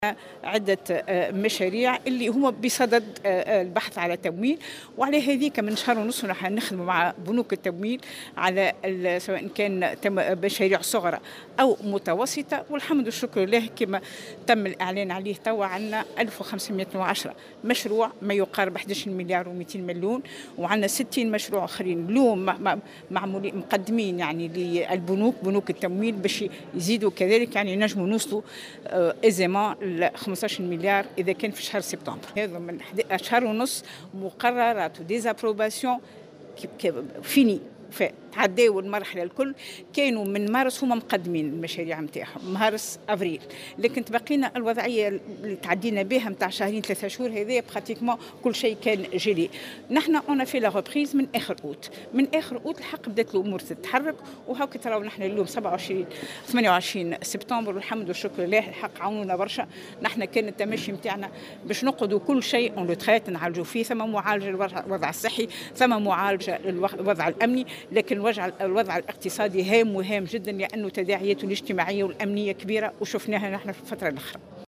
وأضافت في تصريح اليوم لـ"الجوهرة أف أم" على هامش انطلاق فعاليات منتدى الاستثمار والتمويل، أن العمل متواصل لحل الصعوبات التي تواجه عديد المؤسسات في سوسة والتي أصبحت مهدّدة بخسارة مواطن شغل بسبب جائحة كورونا.